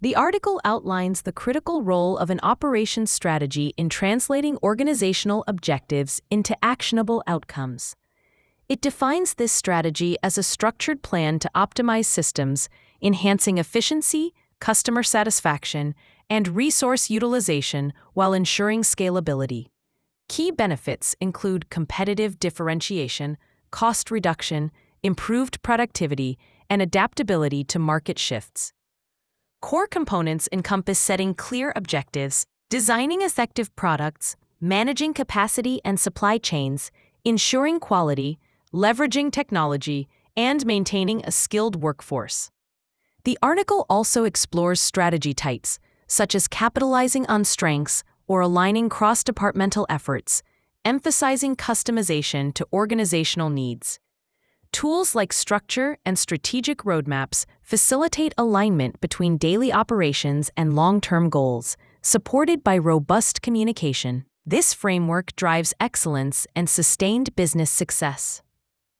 Operations_strategy_blog_AI_overview.mp3